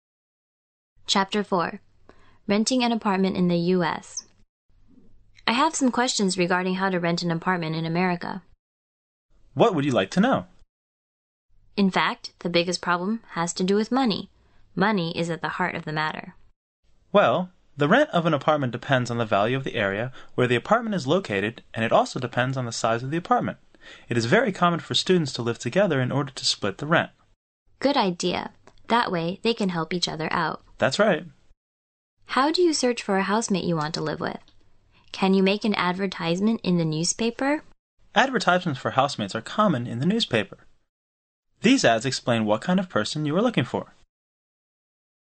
原汁原味的语言素材，习得口语的最佳语境。